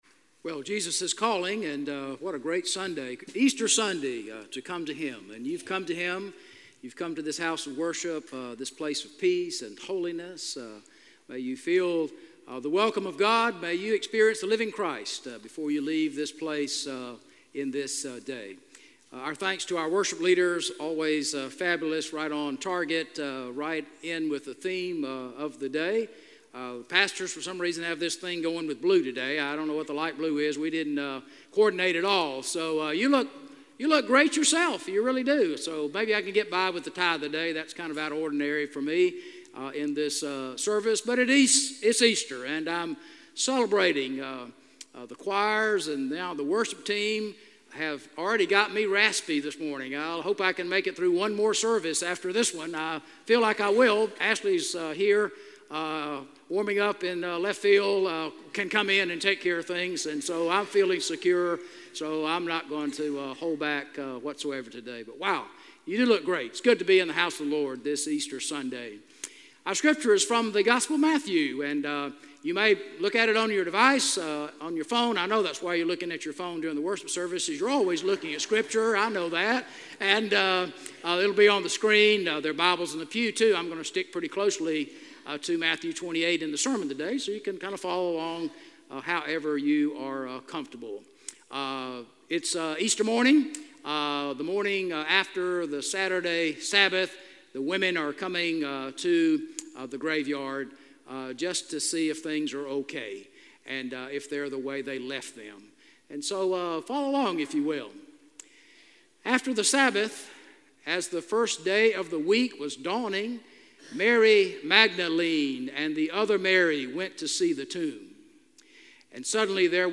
A message from the series "Uncategorized."